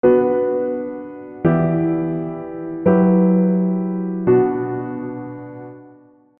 Next is a simple chord progression using the A11 extension in the dominant position, which is commonly found in pop and rock music.
Bm - Em - F#11 (Em/F#) - Bm
Chords: Bm - Em - F#11 (Em/F#) - Bm